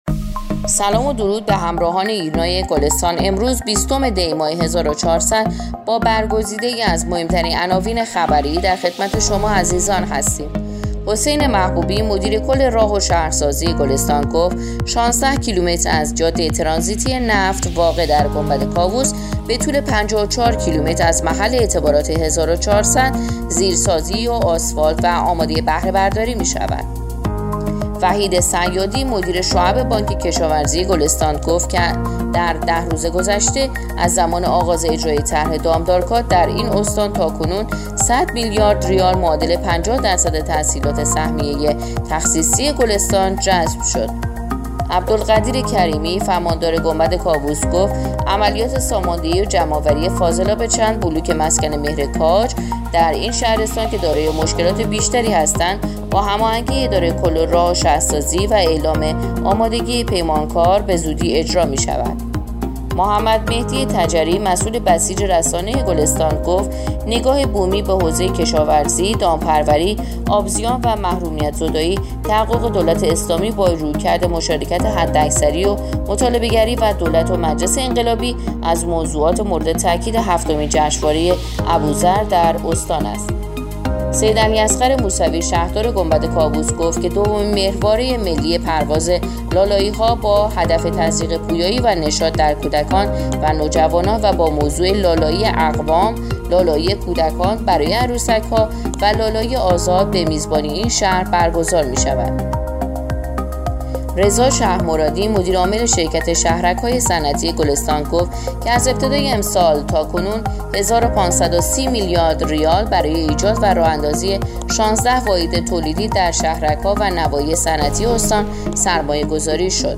پادکست/ اخبار شبانگاهی بیستم دی ماه ایرنا گلستان